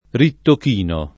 rittochino
[ rittok & no ]